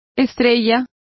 Also find out how estrella is pronounced correctly.